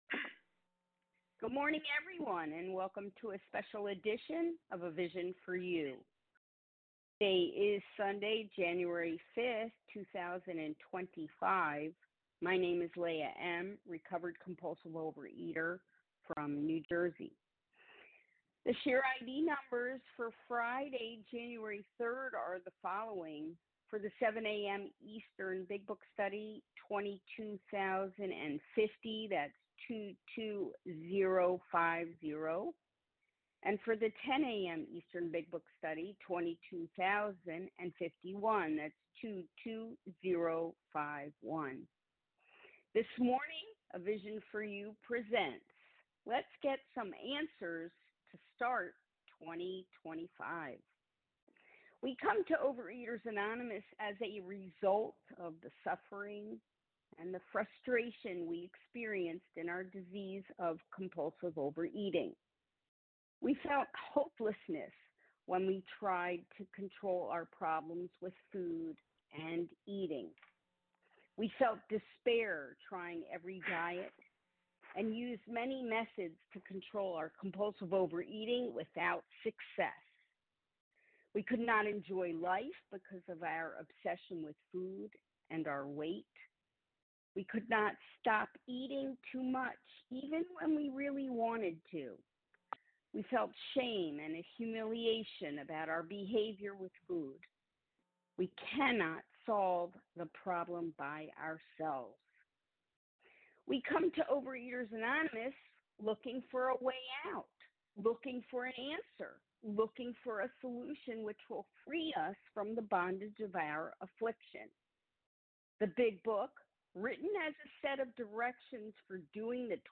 Overeaters Anonymous members share their experience, strength and hope on a number of different topics.